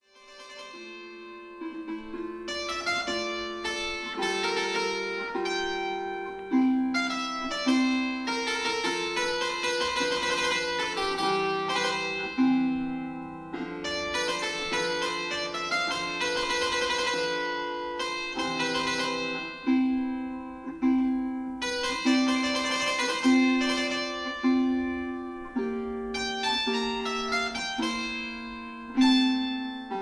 two manual harpsichord
(I: 16,8,4 leather; II: 8 quill, 8 lute)